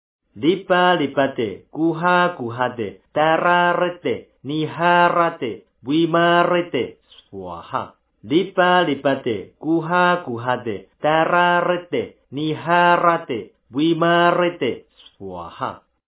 教念版